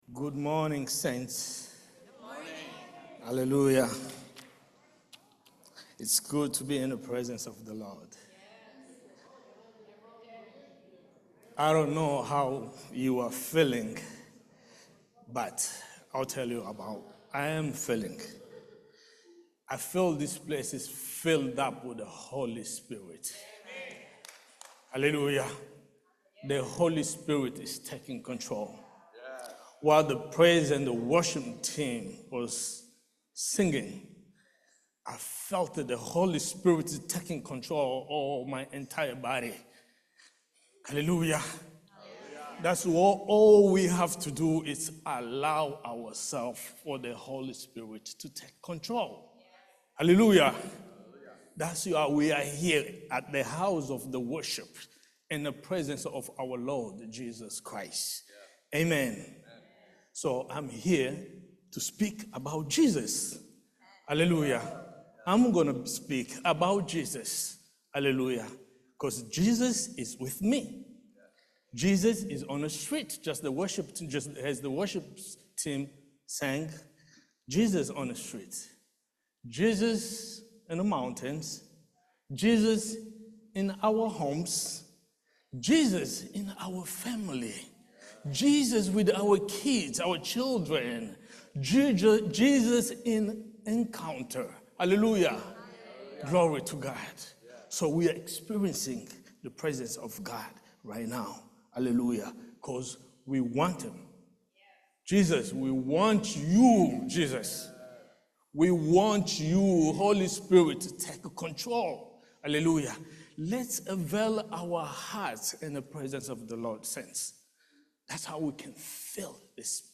Series: Testimony Service